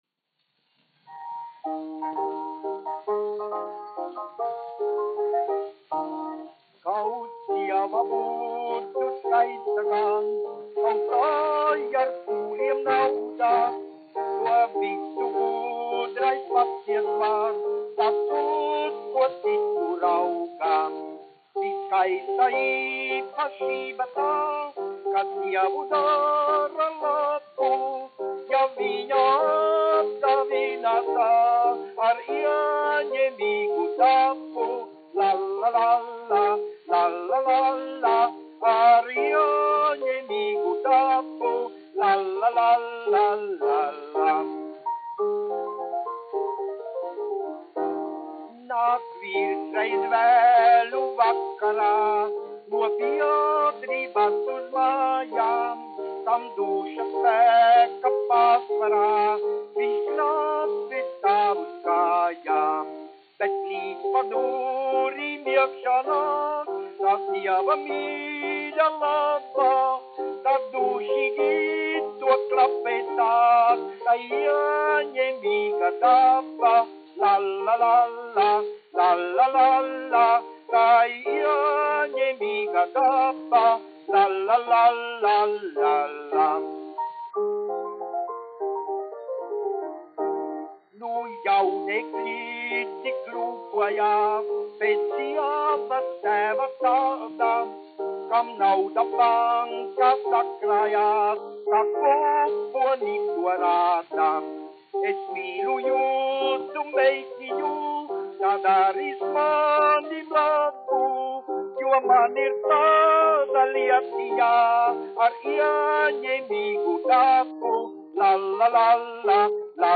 1 skpl. : analogs, 78 apgr/min, mono ; 25 cm
Dziesmas ar klavierēm
Latvijas vēsturiskie šellaka skaņuplašu ieraksti (Kolekcija)